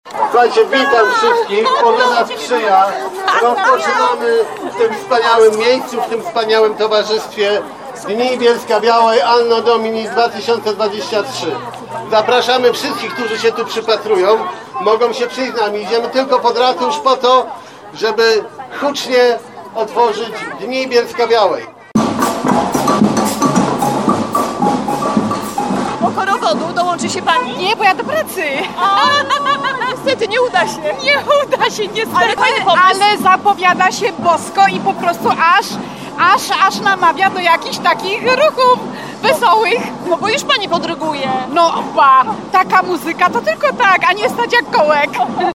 Kolorowa parada mieszkańców przeszła ulicą 11 Listopada. W ten niestandardowy sposób otwarta została tegoroczna edycja Dni Bielska-Białej.